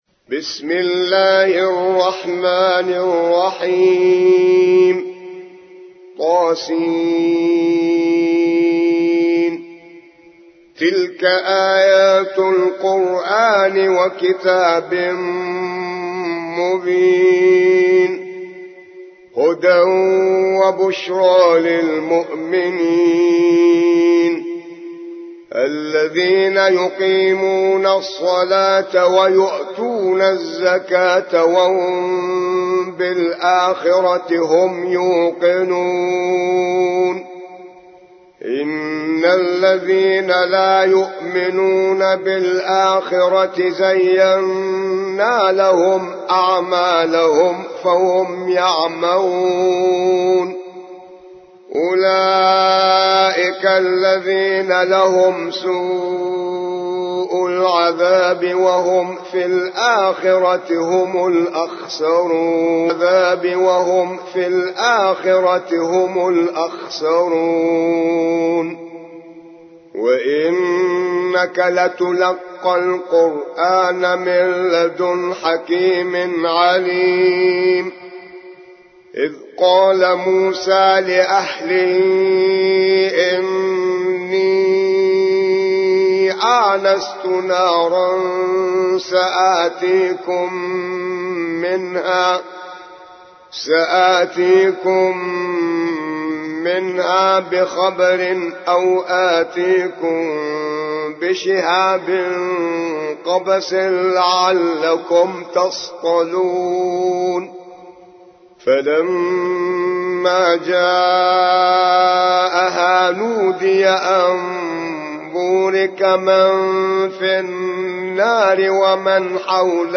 27. سورة النمل / القارئ